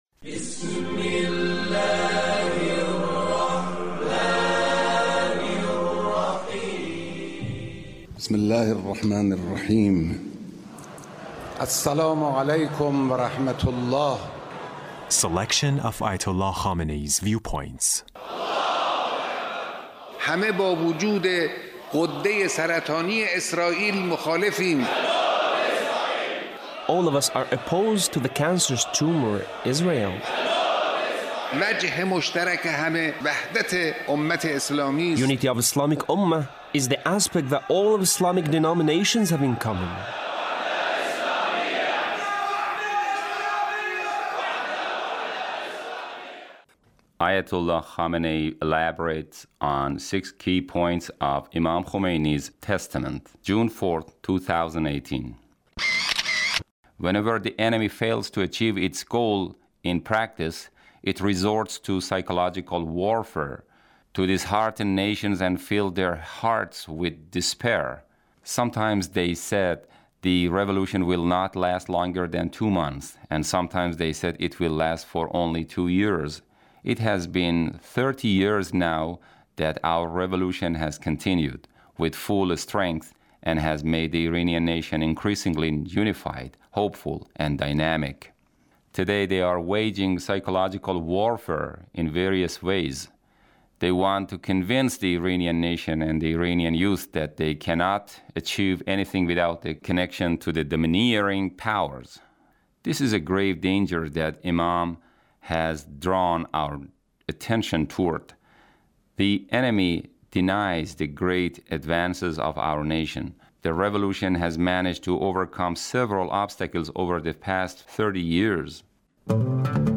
Leader's Speech about Imam Khomeini